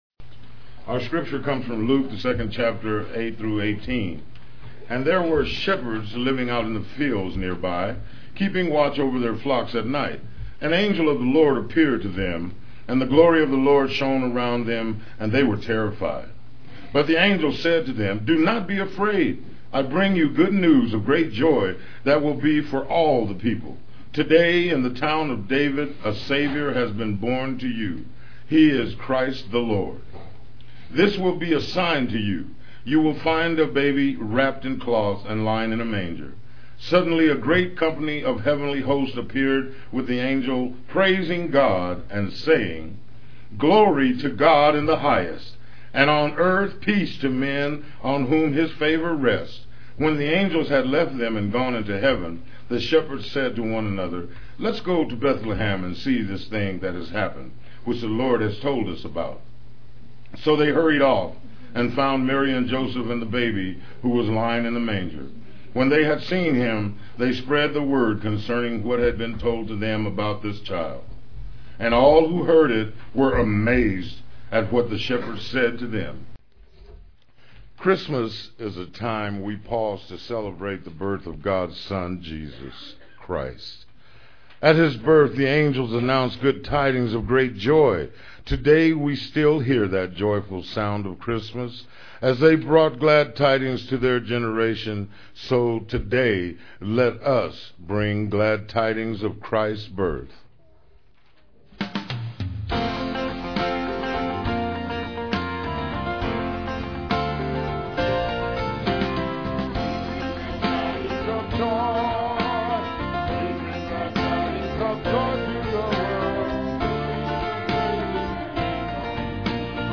PLAY Christmas Alleluia, Dec 17, 2006 Scripture: Luke 2:8-18. Scripture Reading and Narration